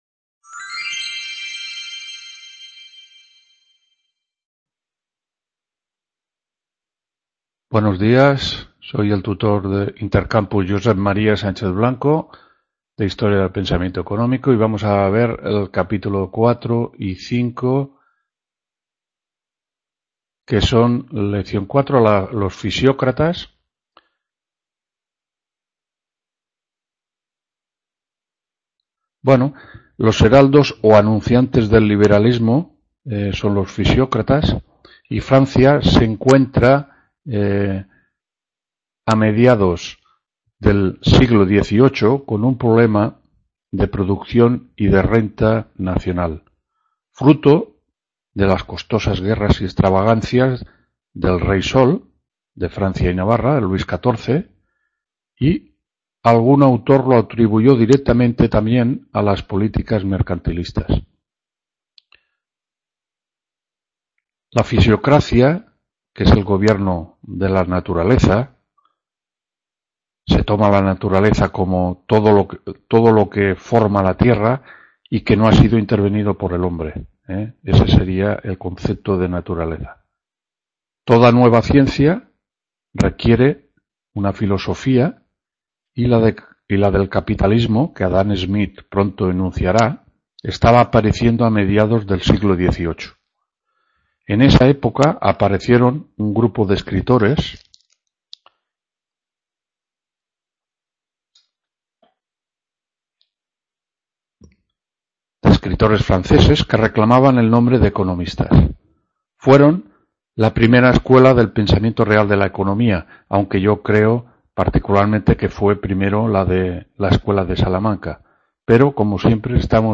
2ª TUTORÍA HISTORIA DEL PENSAMIENTO ECONÓMICO 19-10… | Repositorio Digital